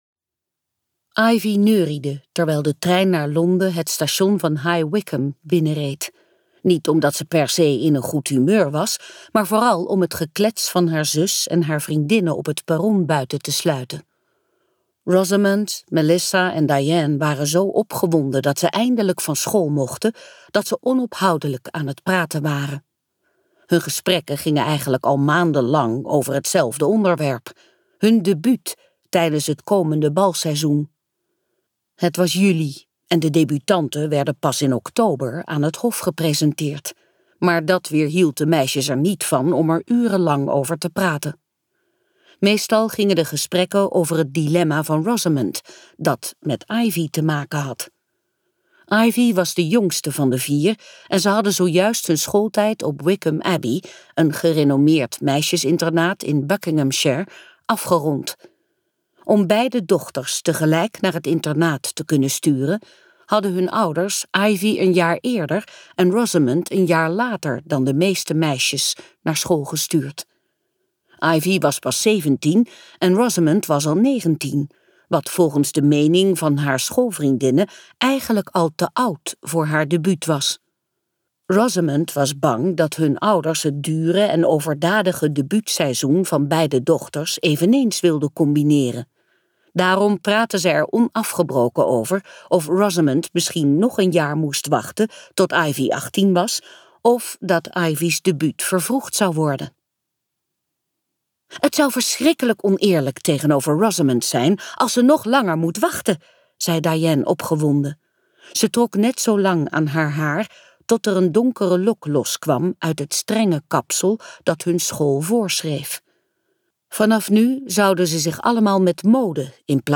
Uitgeverij De Fontein | Dochter van twee werelden luisterboek